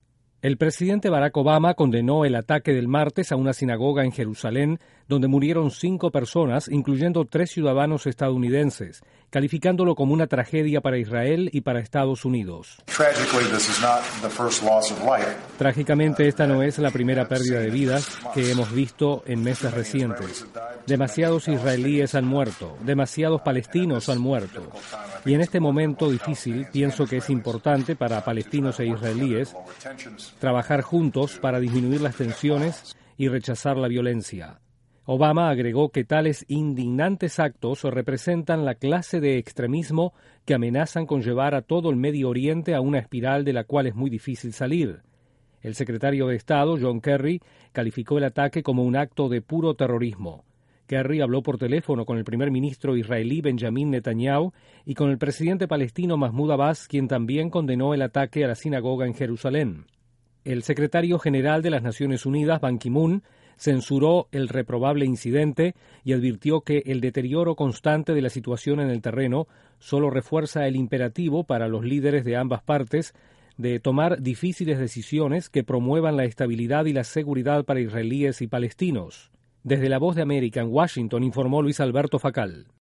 El ataque a una sinagoga en Jerusalén que causó cinco muertos generó reacciones de rechazo a nivel mundial. Desde la Voz de América en Washington informa